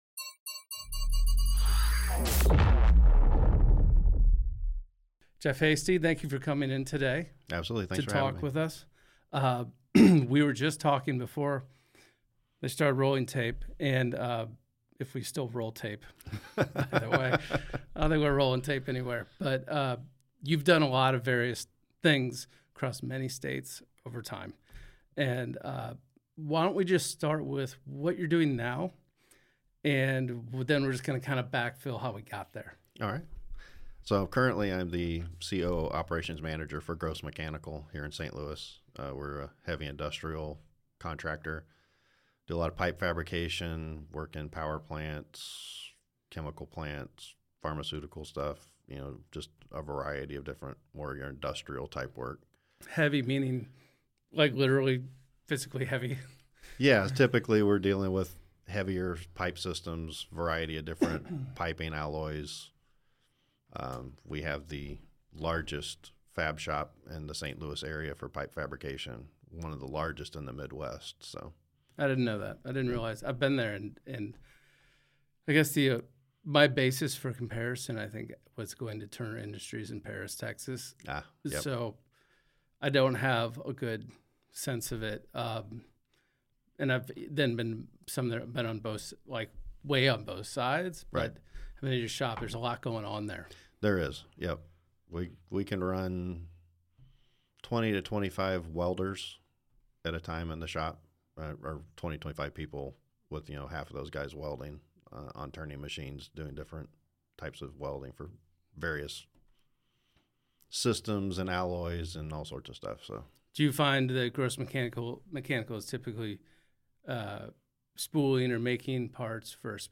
Tune in for a conversation about pivots, leadership, and the unique life experiences that shape our industry.